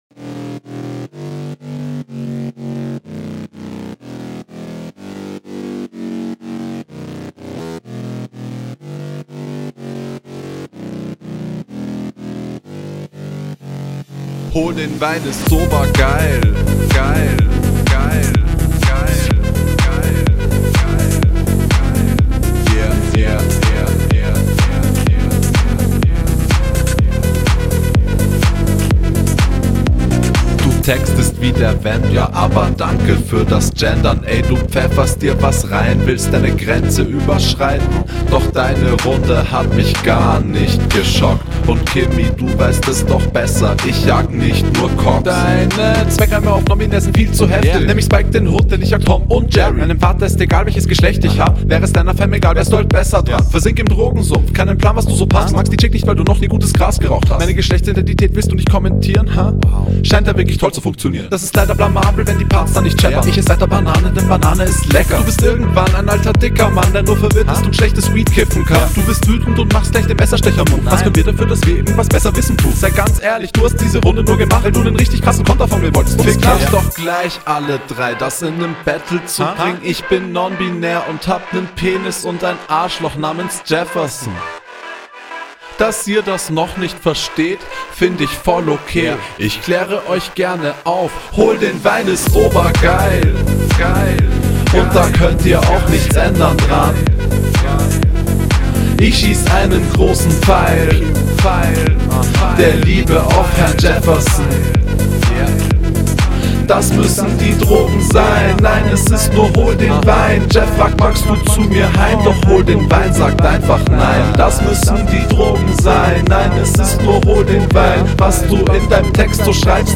Flow: Klingt meiner Meinung nach ein wenig Off.